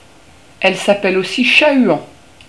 La chouette hulotte